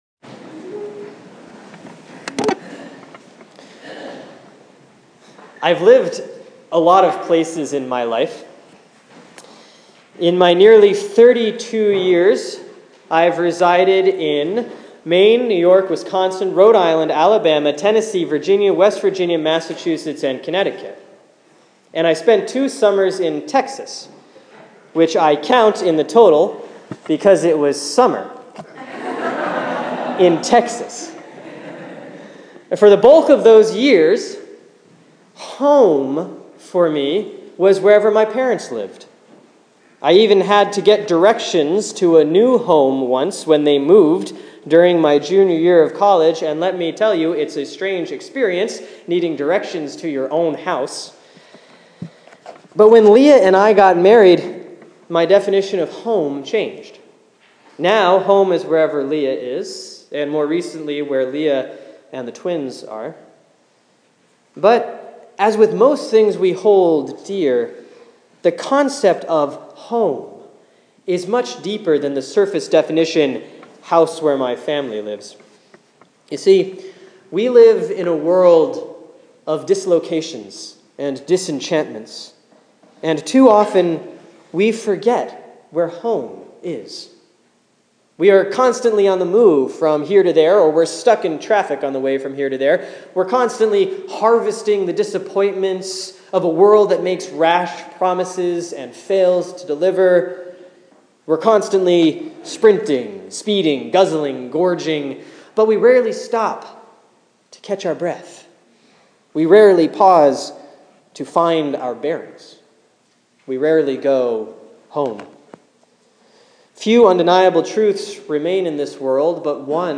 Sermon for Sunday, January 4, 2015 || Christmas 2